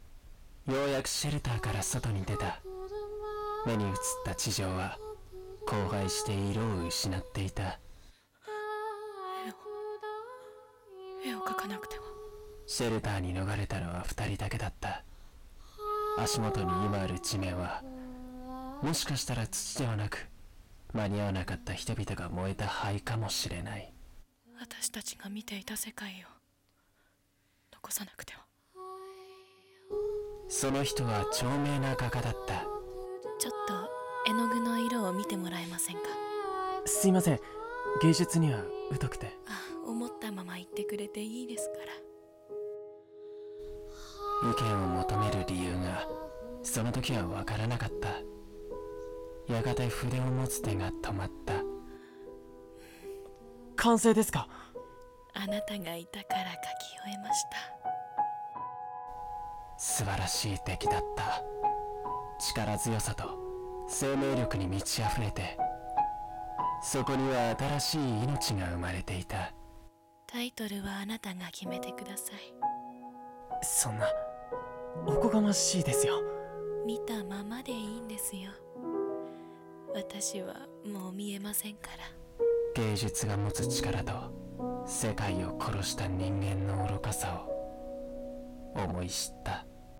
世界を、絵に残す。【二人声劇】 演◆